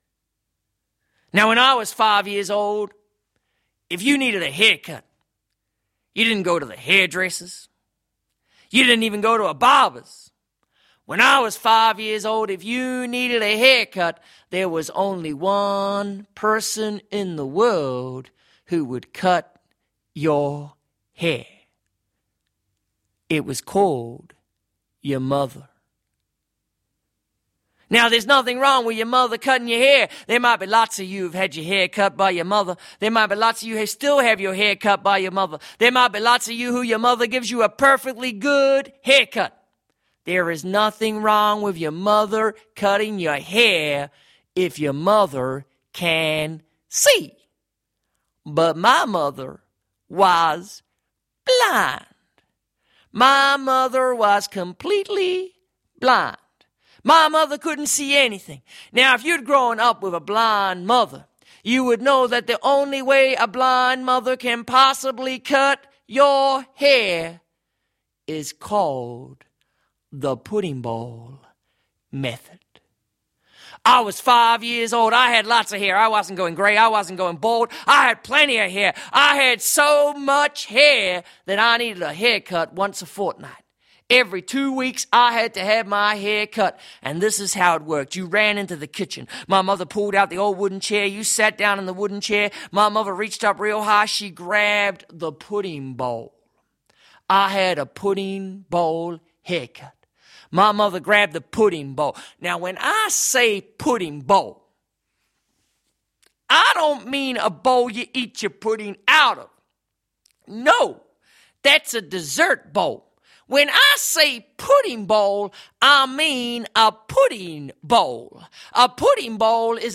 They provide a guide to the storytelling performance, however they do not capture the full impact of a live performance.